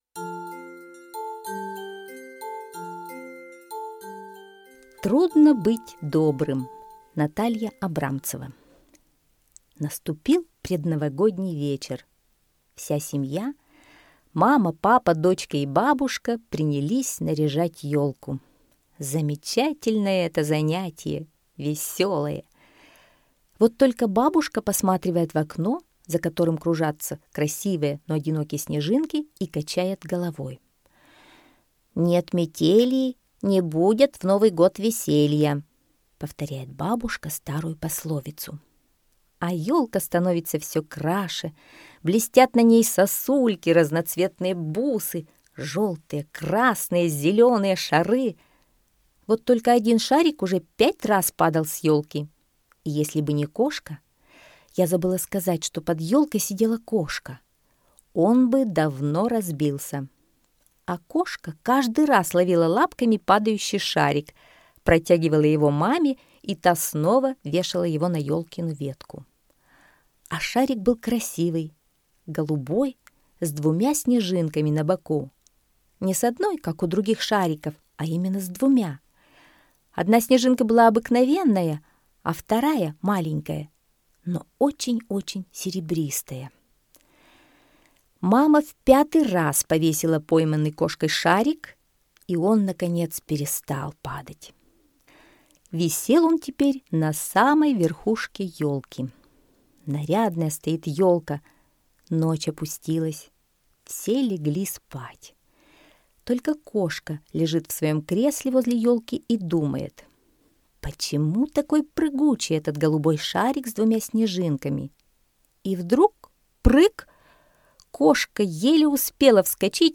Аудиосказка «Трудно быть добрым»